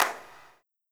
Car Wash Clap1.wav